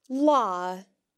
The broad L sound is made by resting your tongue behind your front teeth while pronouncing the letter, and occurs when the L is next to an a, o, or u. The broad L can be heard in latha (a day):